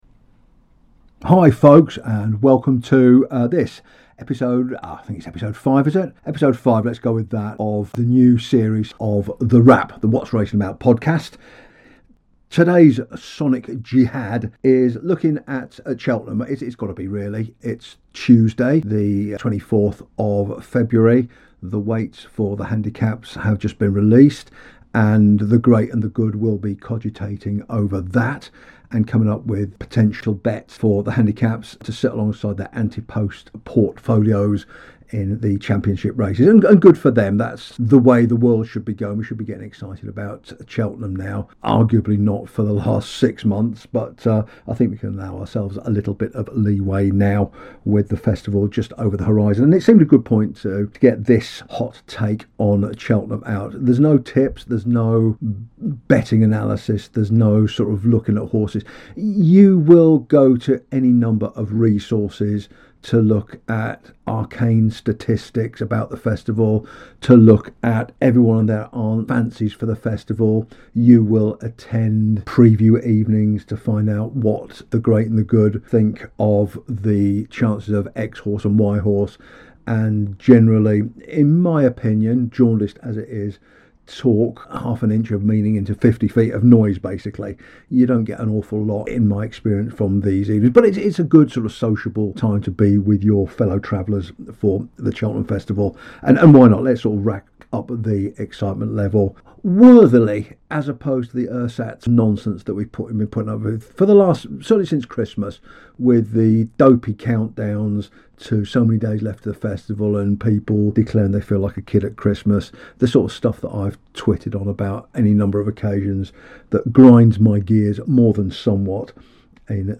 Bit of heavy metal too, natch.